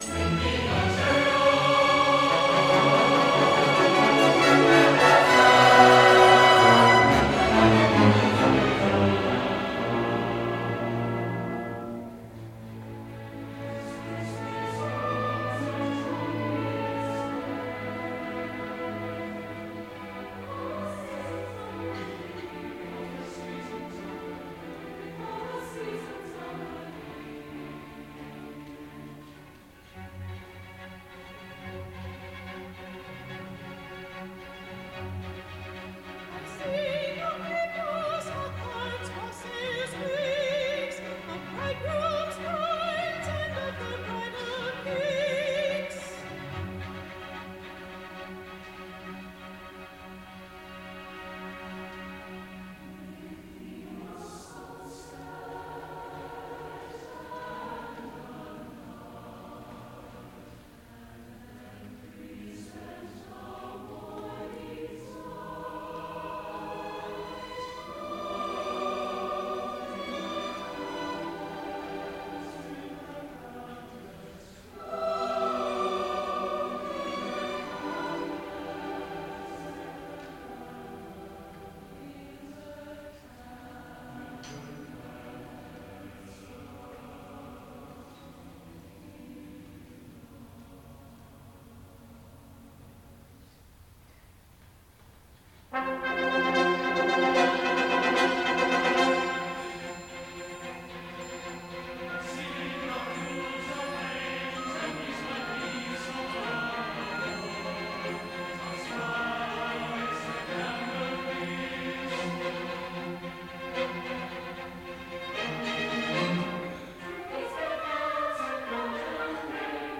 for Soprano, Baritone, Chorus and Orchestra